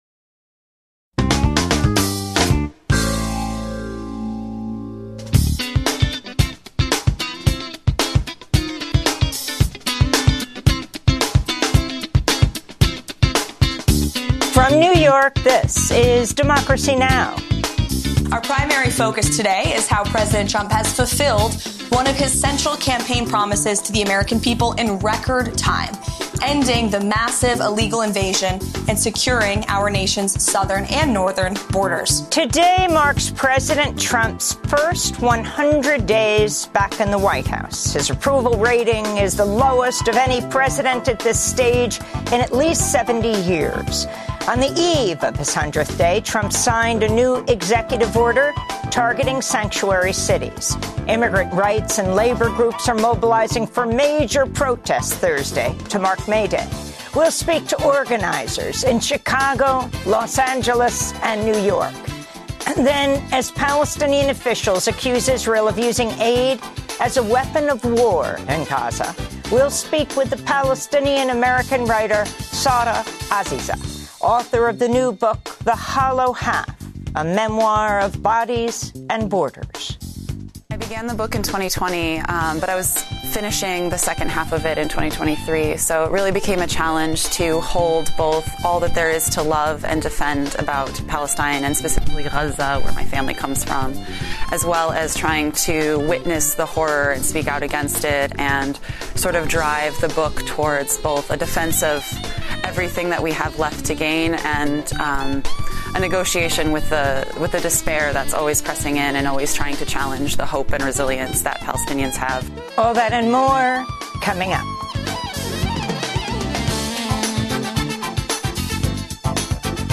A daily TV/radio news program, hosted by Amy Goodman and Juan Gonzalez.
Genres : News , talk